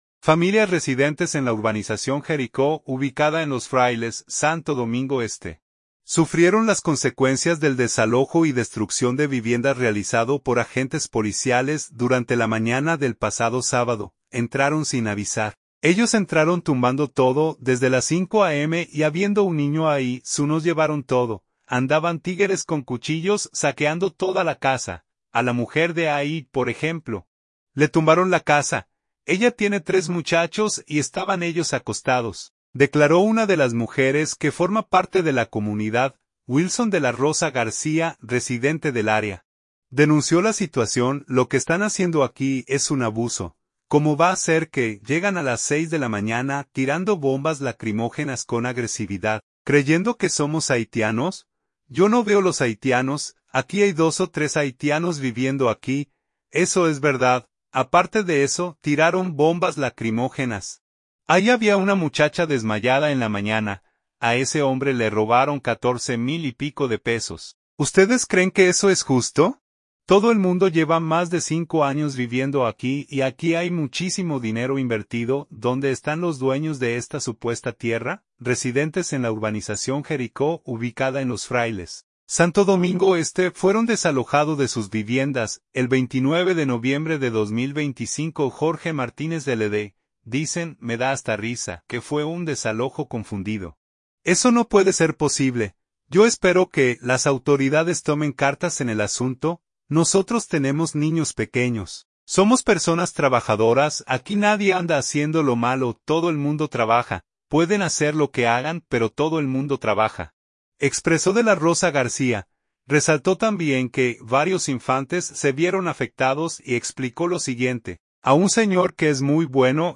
“Entraron sin avisar…”: Testimonios de afectados de desalojos en Los Frailes